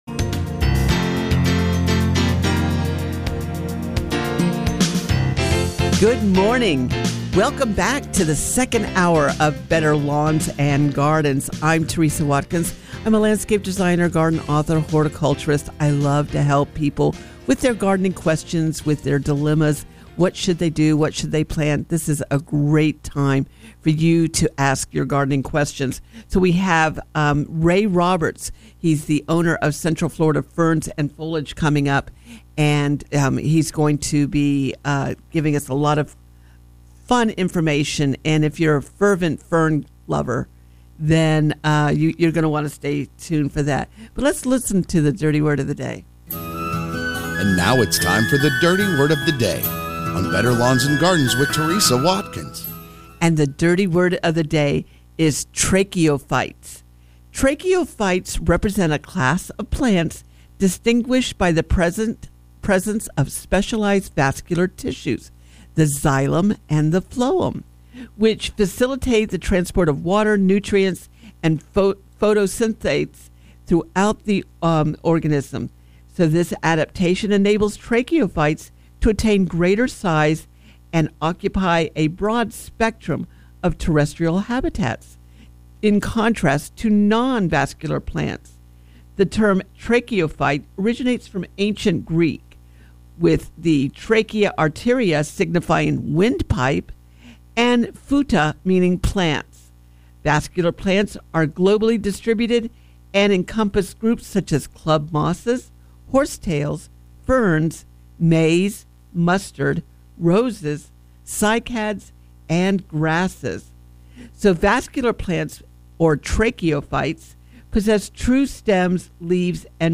Better Lawns and Gardens Hour 2 – Coming to you from the Summit Responsible Solutions Studios.